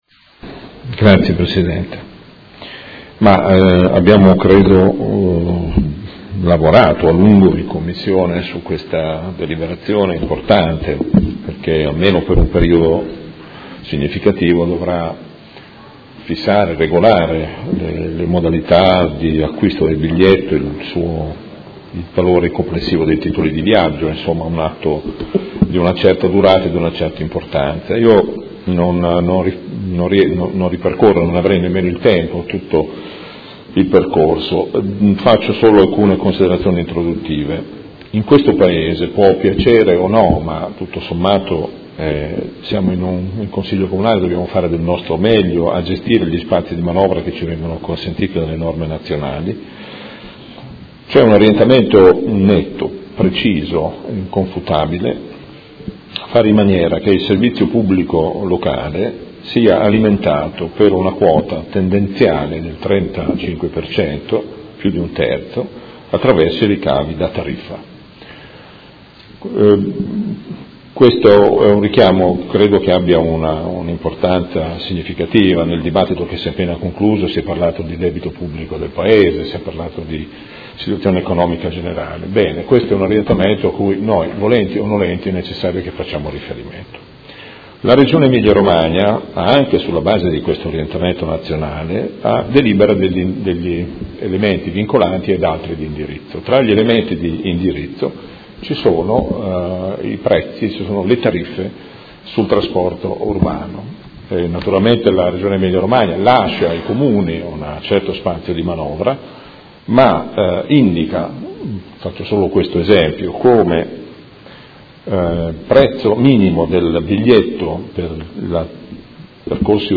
Gabriele Giacobazzi — Sito Audio Consiglio Comunale
Seduta del 21/07/2016 Proposta di deliberazione: Indirizzi per la gestione del Trasporto Pubblico Locale – Adeguamenti tariffari per il Comune di Modena a decorrere dal 01.08.2016